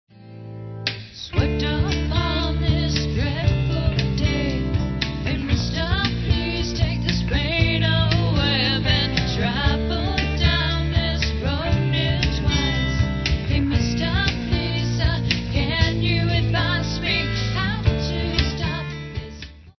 rock
stereo